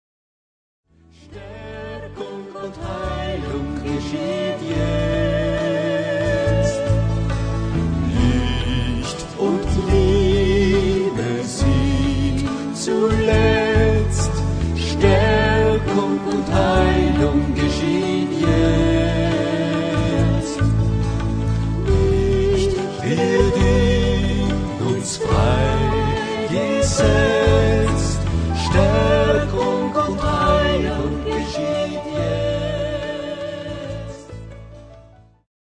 Ihr ausgezeichneter und vielseitiger Pianist